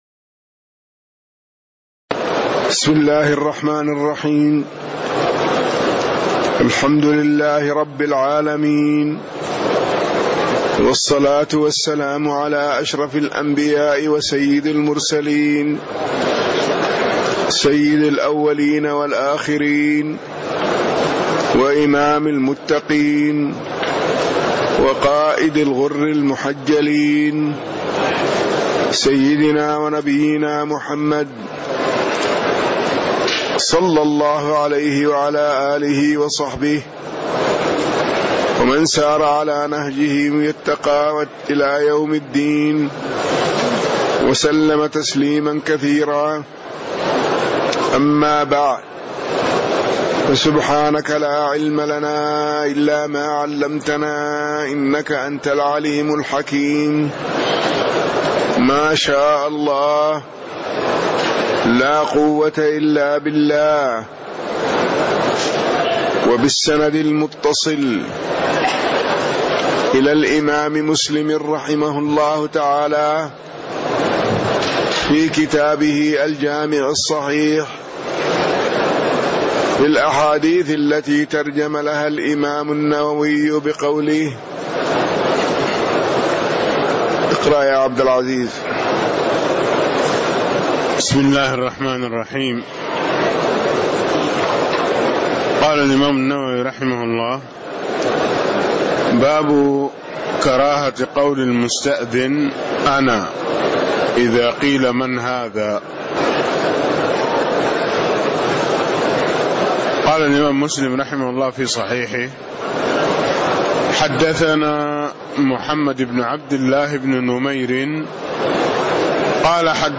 تاريخ النشر ٢٢ ذو الحجة ١٤٣٦ هـ المكان: المسجد النبوي الشيخ